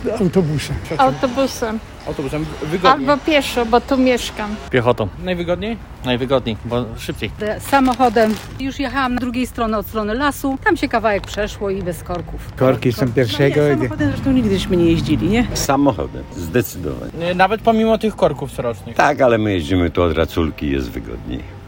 Zapytaliśmy zielonogórzan jak dotrą tego dnia na nekropolie. Jak się okazuje część mieszkańców wybiera komunikacje miejską, inni własne nogi lub samochód: